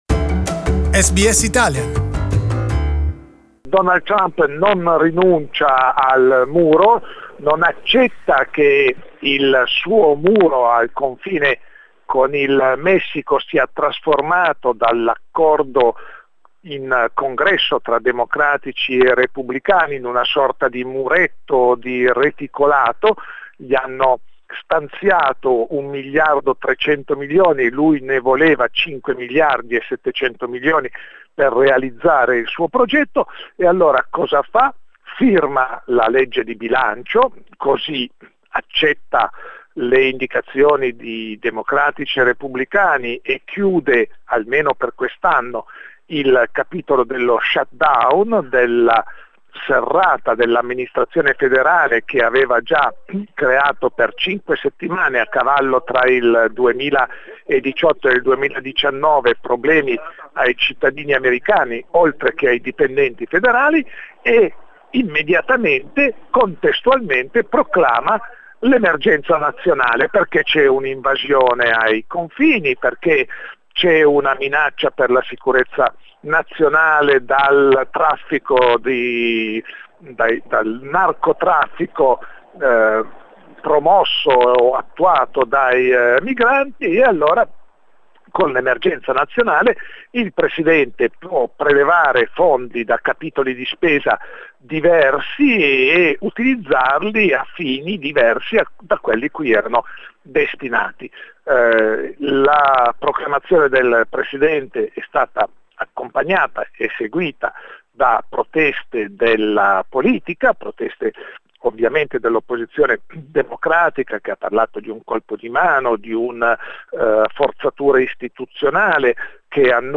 Our expert on US issues, journalist